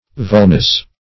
\Vul*nose"\